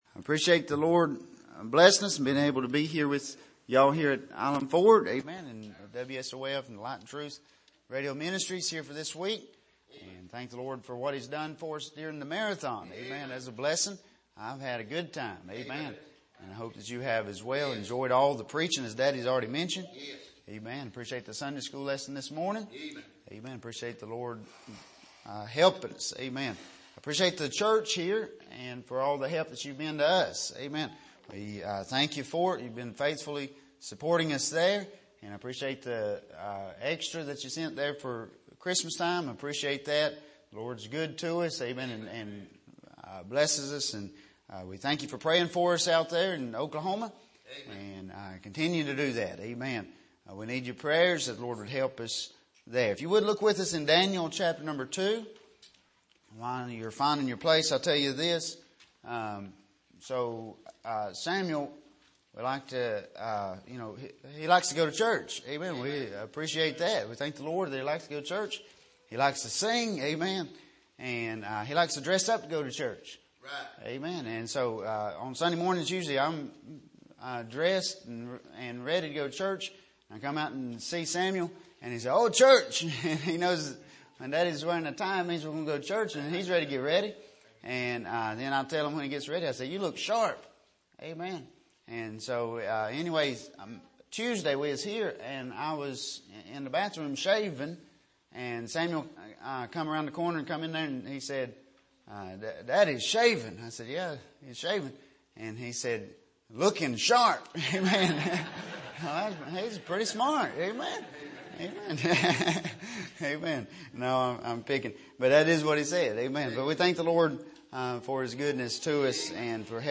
Sermon Archive
Here is an archive of messages preached at the Island Ford Baptist Church.